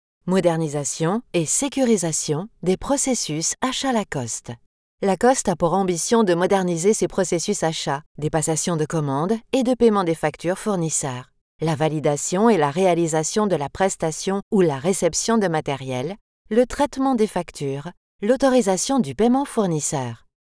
Female
French (Parisienne)
Adult (30-50)
Lacoste - Elearning